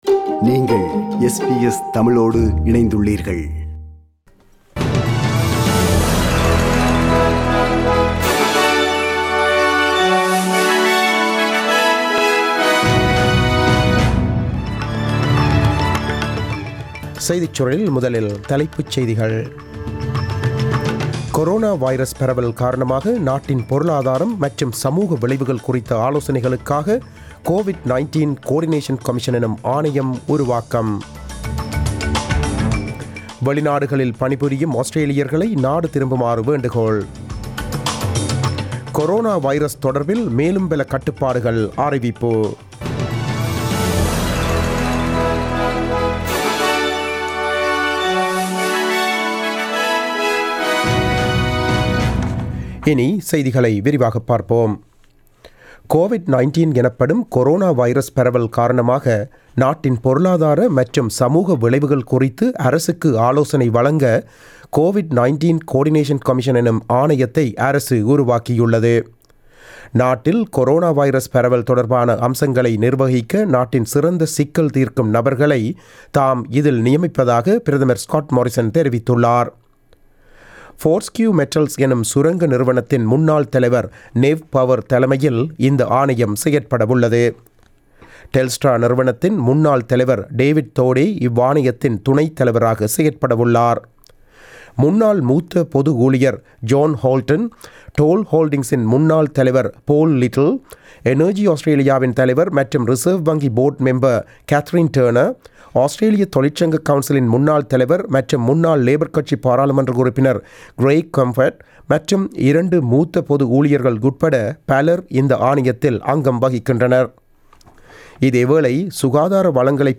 நமது SBS தமிழ் ஒலிபரப்பில் இன்று புதன்கிழமை (25 March 2020) இரவு 8 மணிக்கு ஒலித்த ஆஸ்திரேலியா குறித்த செய்திகள்.